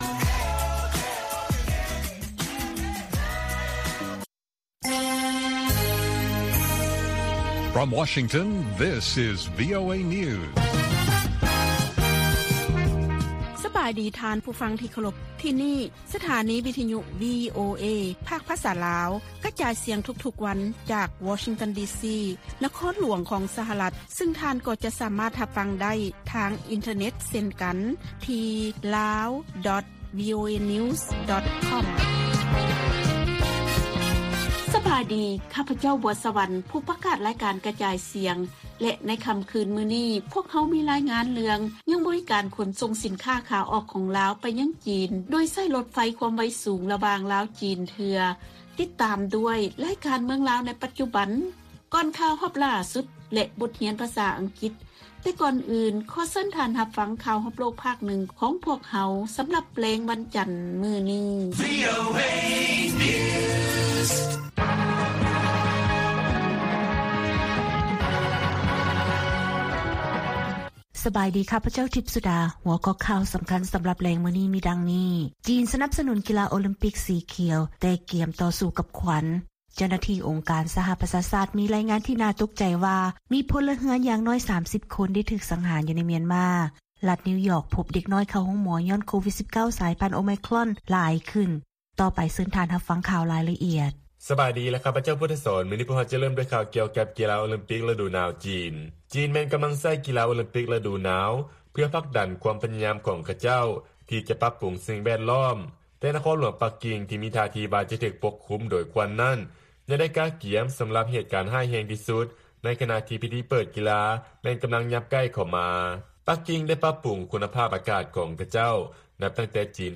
ລາຍການກະຈາຍສຽງຂອງວີໂອເອ ລາວ: ຈີນ ສະໜັບສະໜູນ ກິລາໂອລິມປິກ ສີຂຽວ, ແຕ່ກຽມຕໍ່ສູ້ກັບຄວັນ